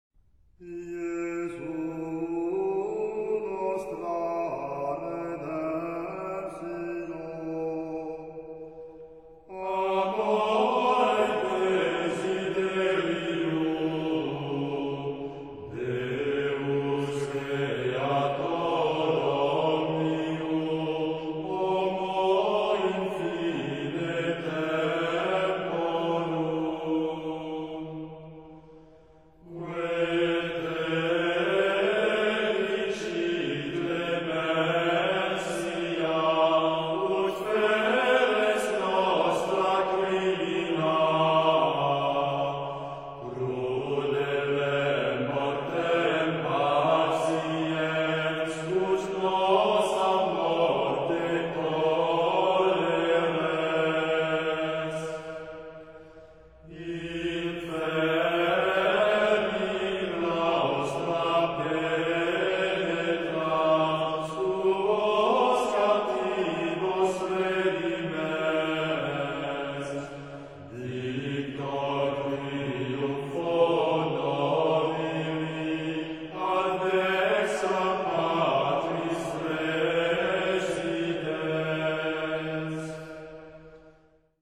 Мистерия XIII в. Запись 1995 г.